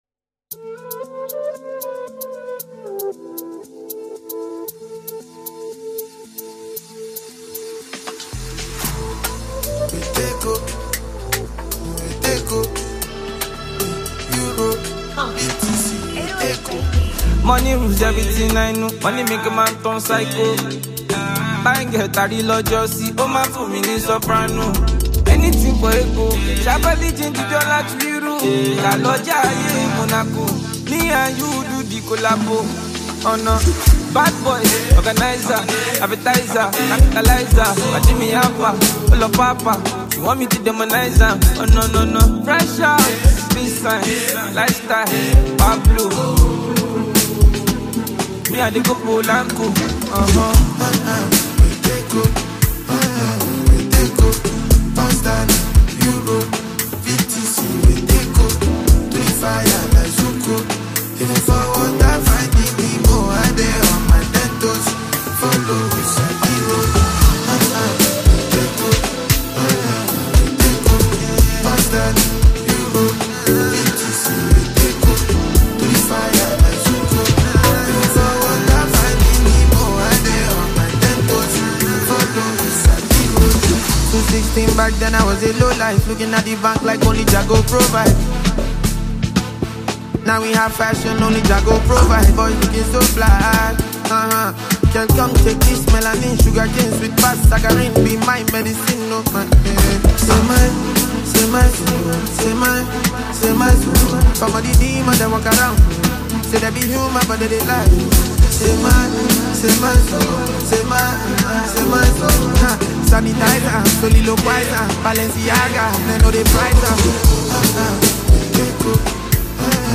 club masterpiece